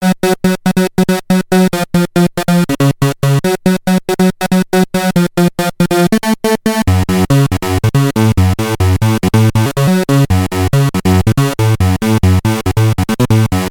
Especially for this tune it makes sense to play two oscillators with a lower octave for more bass.
Play some notes - still boring, hm?